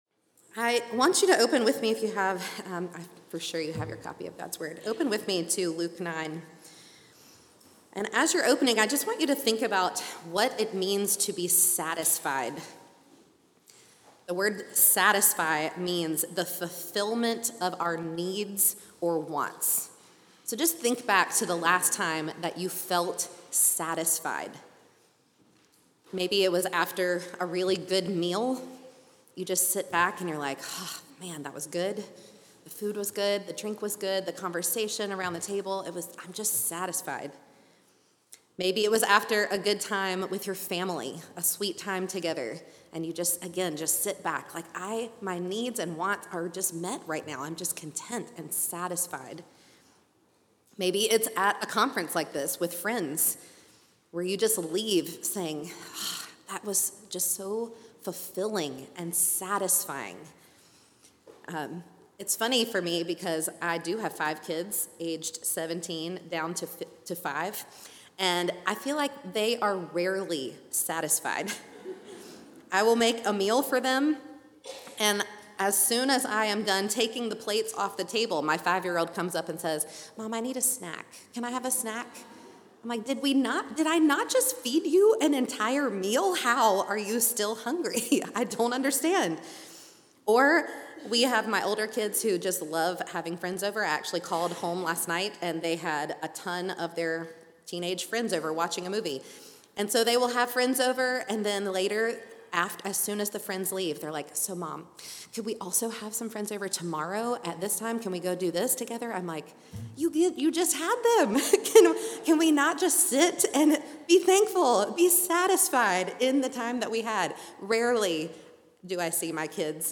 Audio recorded at Feed My Sheep for Pastors Wives Conference 2024.